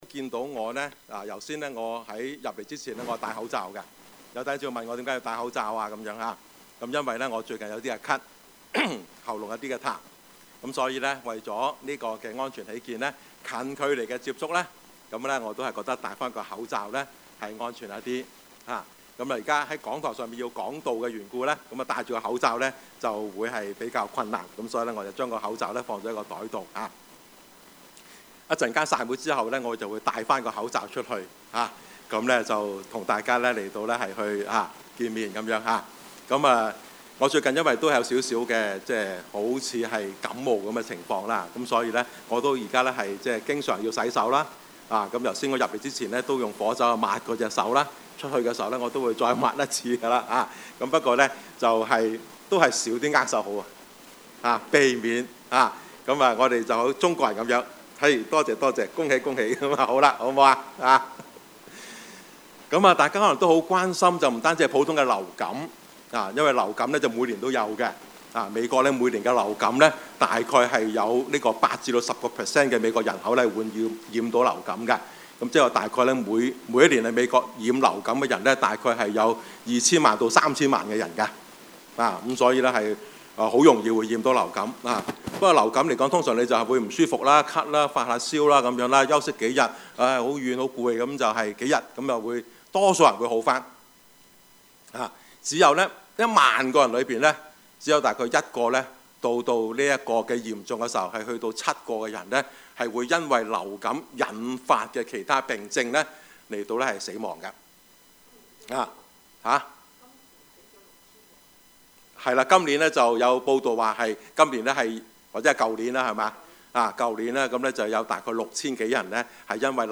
Service Type: 主日崇拜
Topics: 主日證道 « 流言蜚語，人身攻擊 把神的話藏在心裏 »